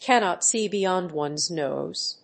アクセントcannót sée beyònd [fùrther than] one's nóse